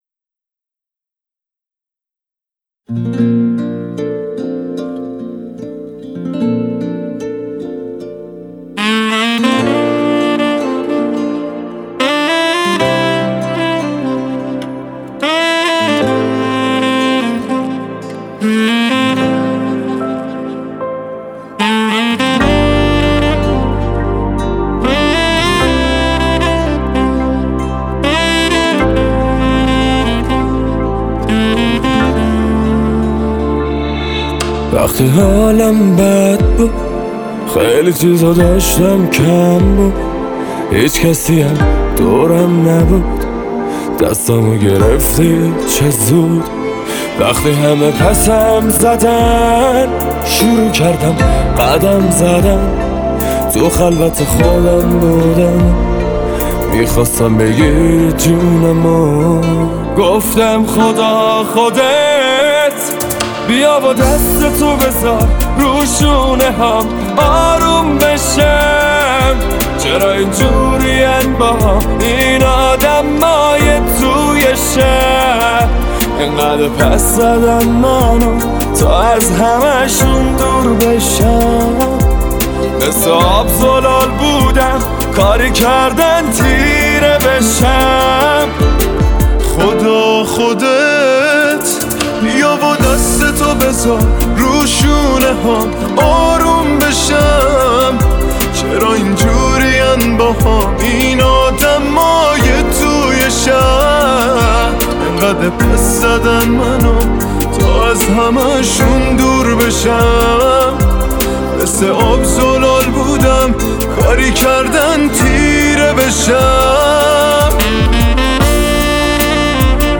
یک گروه موسیقی پاپ